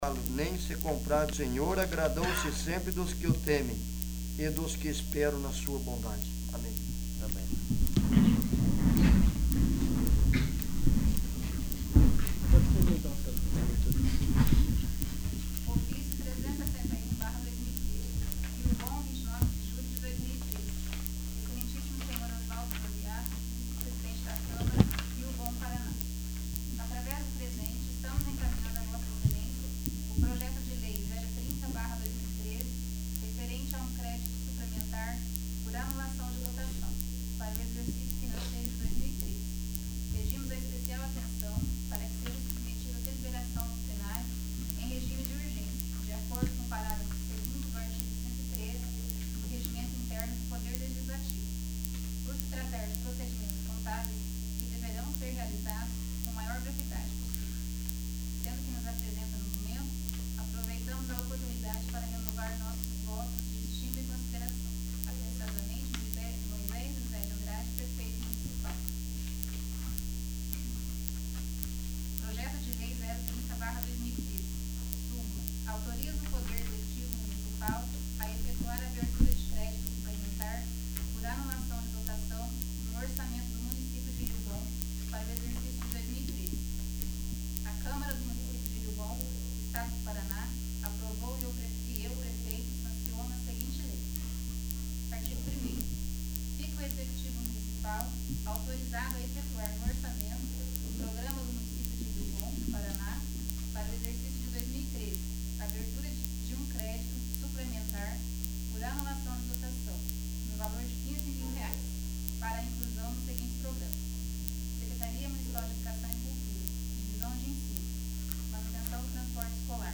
21º. Sessão Extraordinária